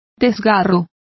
Complete with pronunciation of the translation of lacerations.